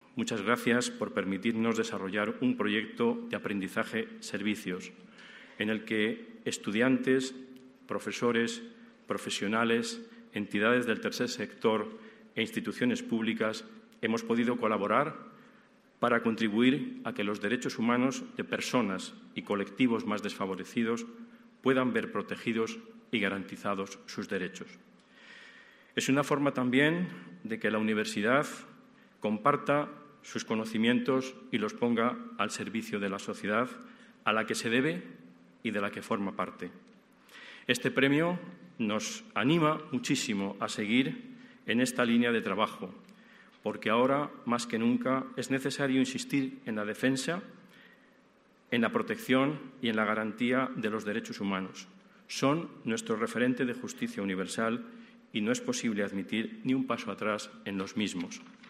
Los Premios Solidarios ONCE Edición Especial 2021Abre Web externa en ventana nueva homenajearon, el pasado 1 de diciembre, la solidaridad y fuerza de la sociedad castellano y leonesa, durante la ceremonia de entrega de galardones que se celebró en el Teatro Zorrilla de Valladolid.